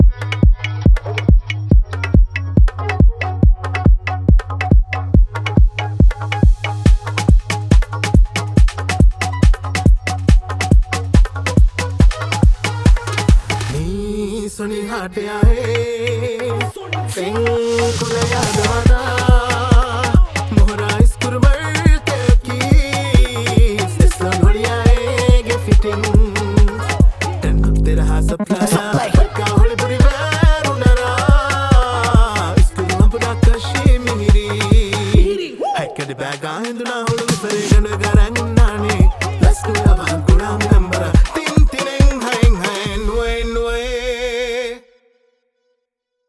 Bollywood Fusion